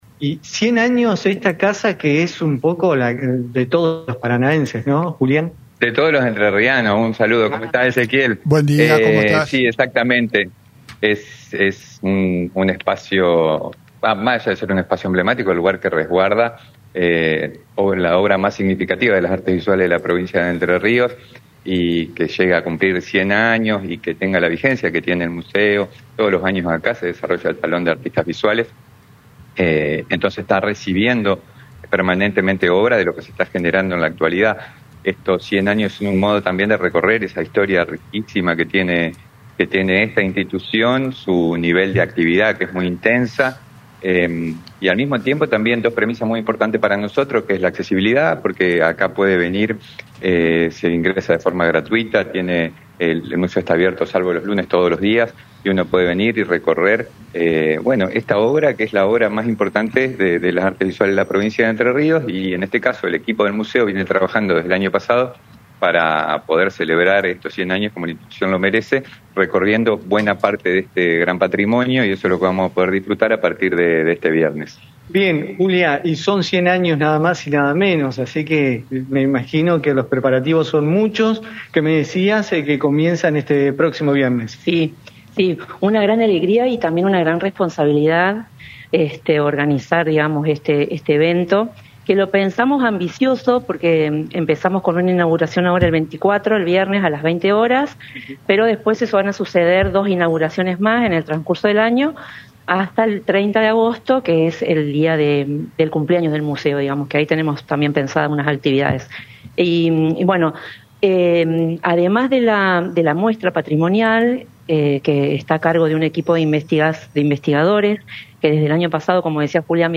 resaltó el funcionario en declaraciones al móvil de Radio Costa Paraná (88.1)